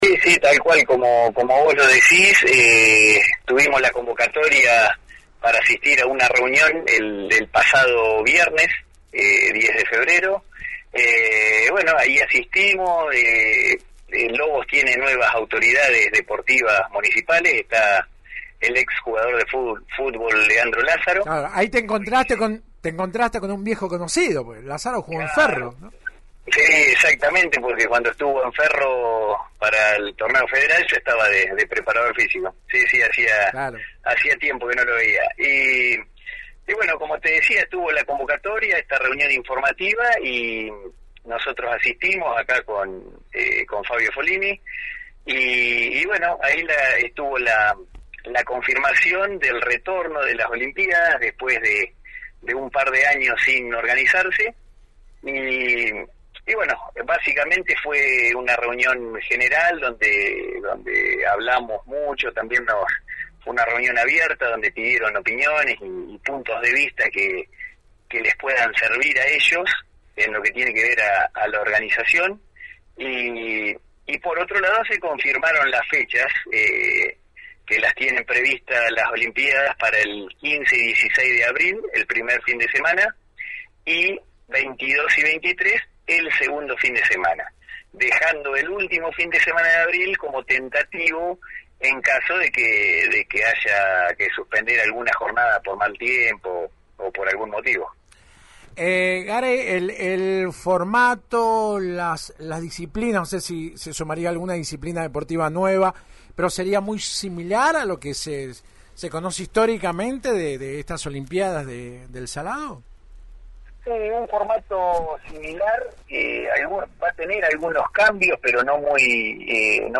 Consultado por la 91.5, el secretario de deportes local, Prof. Fernando Muñiz, señaló este martes que fue «una reunión muy positiva.